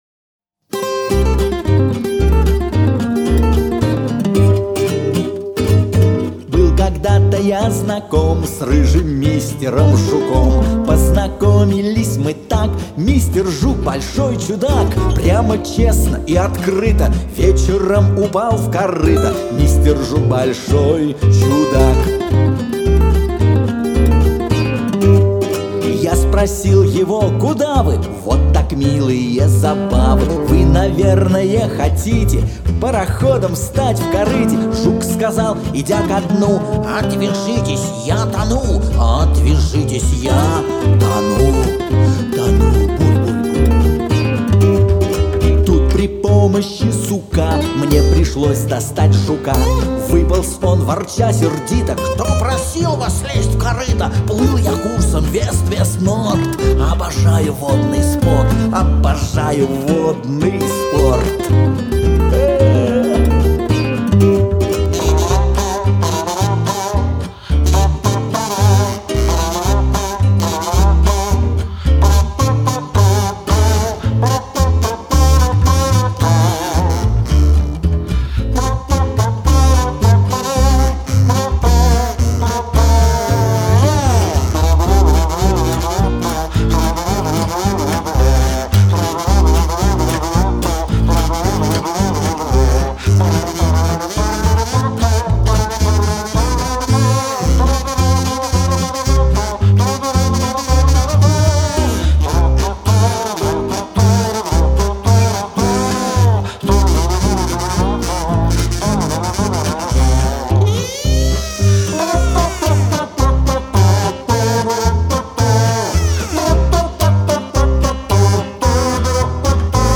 музыка и исполнение